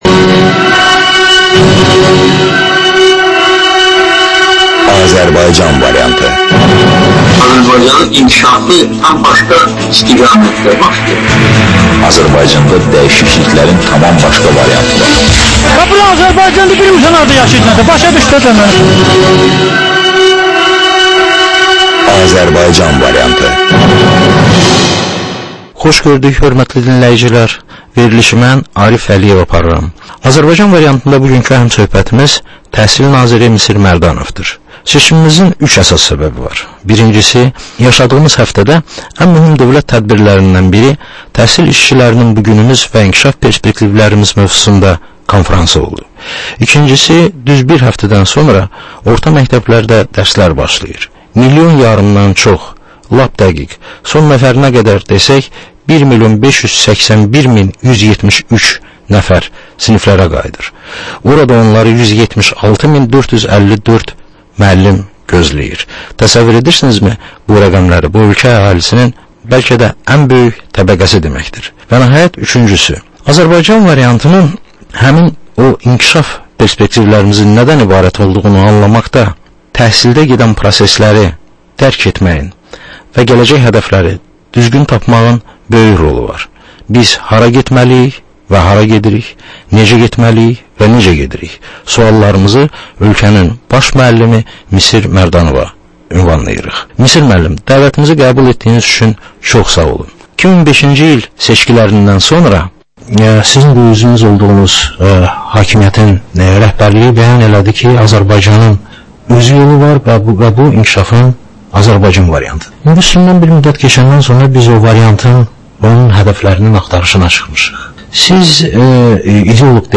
Həftənin aktual məsələsi haqda dəyirmi masa müzakirəsi